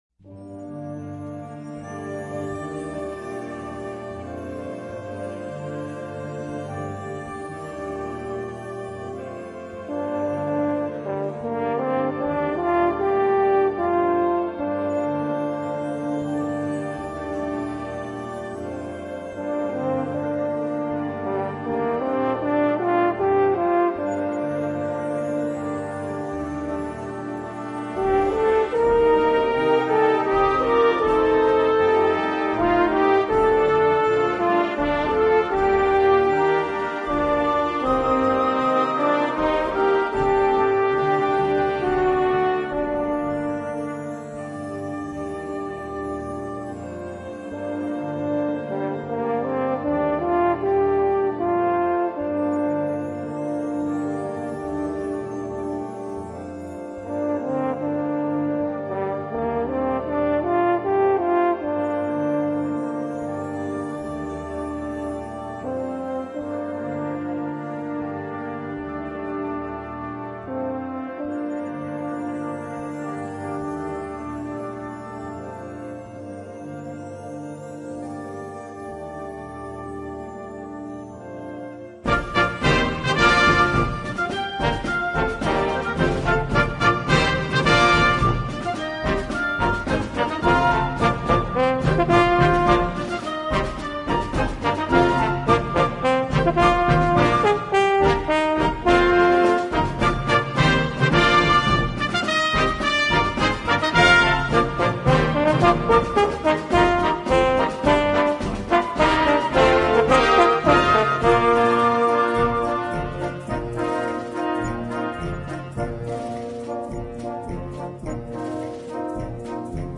Voicing: Instrument Solo w/ Band